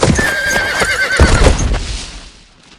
horses
rear4.ogg